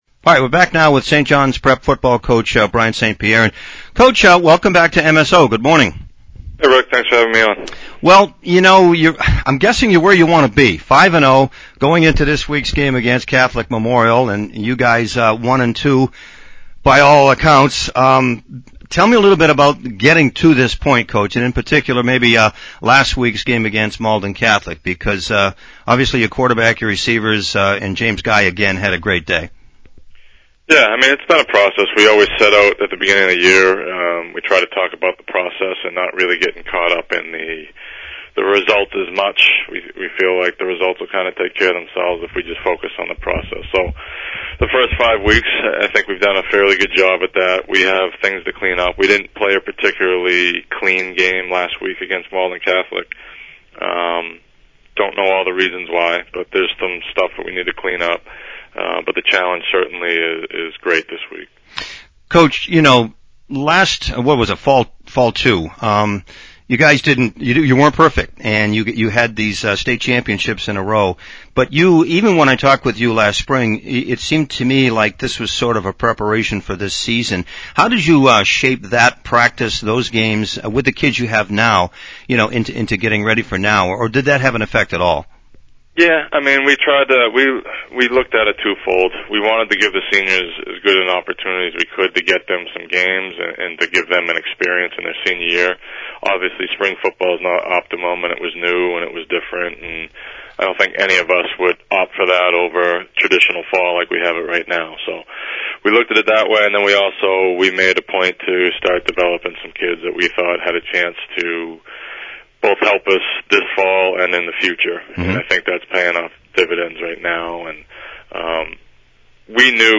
(Audio) Post-game, Pre-game